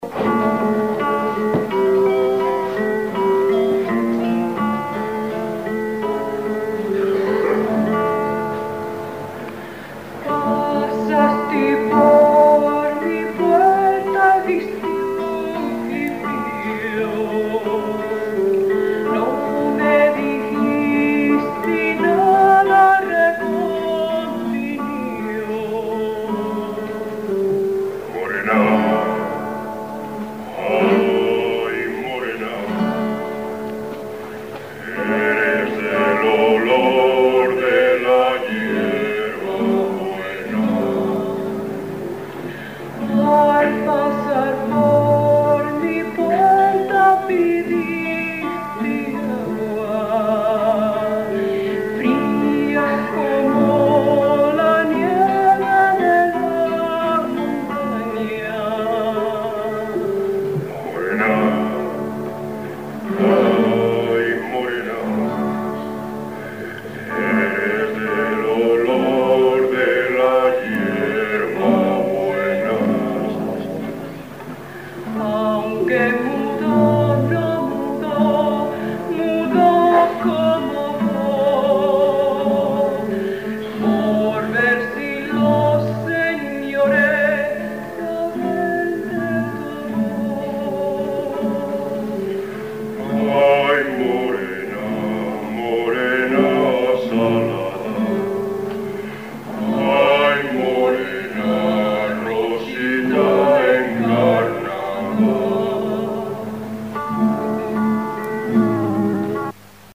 realizado en el Salón de Actos del Seminario de Toro (Zamora)
en directo, con un Cassete Philips de petaca.
VOZ
GUITARRAS
PERCUSIÓN